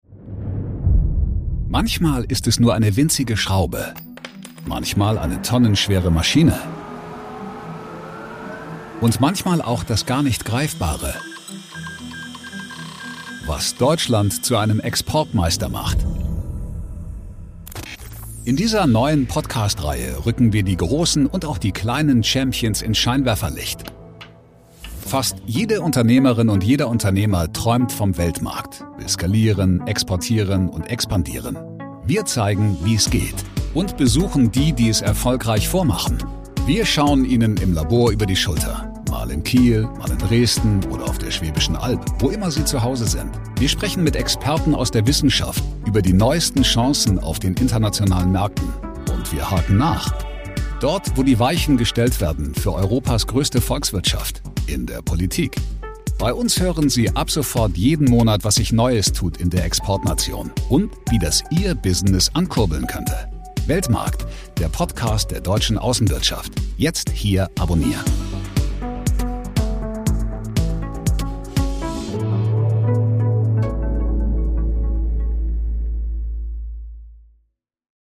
Bei uns hören Sie in Reportagen, Berichten und Interviews ab sofort